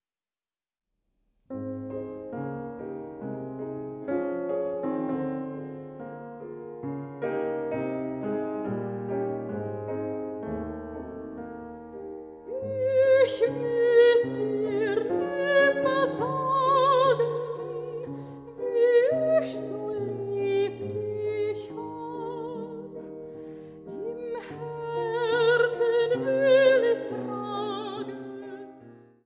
Mezzosopran
Tenor
Klavier